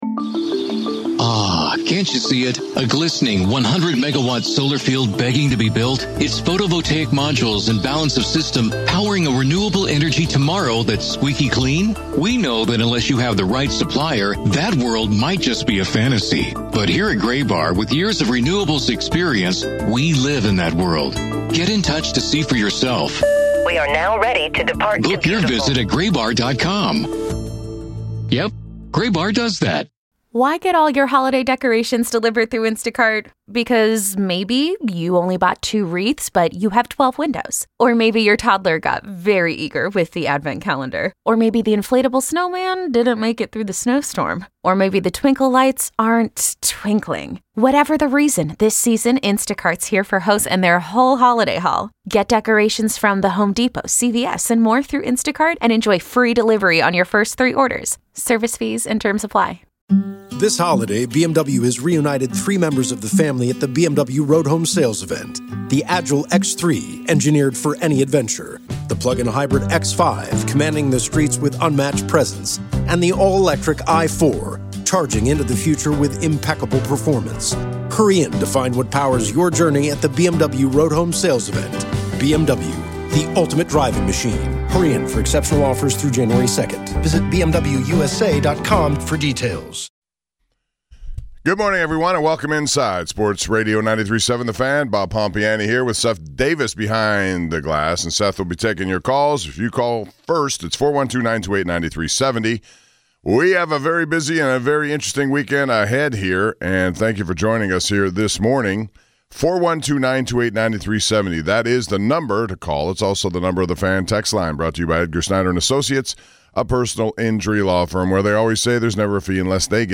Governor Josh Shapiro joins the show! Josh calls this game the PA Bowl.